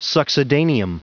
Prononciation du mot succedaneum en anglais (fichier audio)
Prononciation du mot : succedaneum